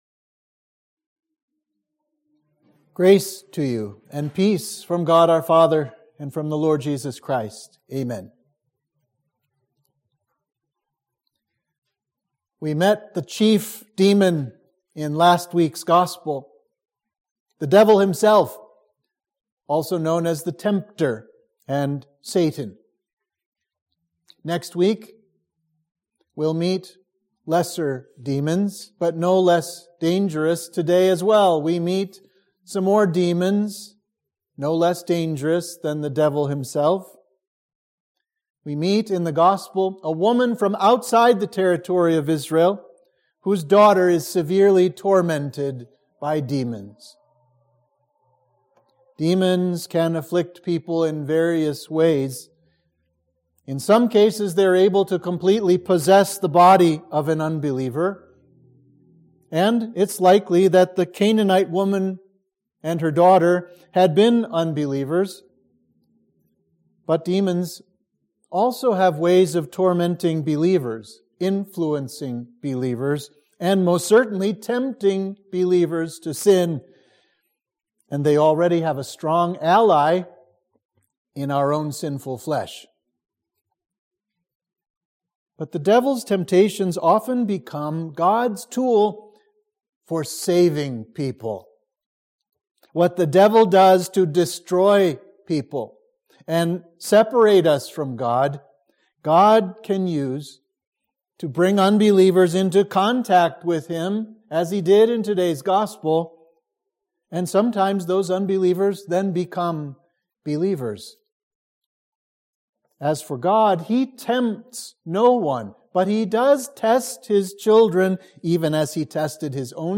Sermon for Reminiscere – Lent 2